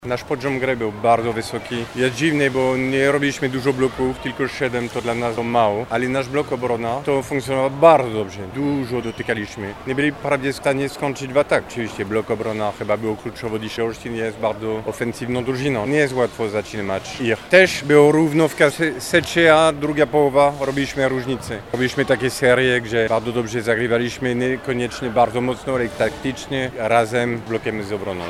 – analizował szkoleniowiec mistrzów Polski, Stephane Antiga.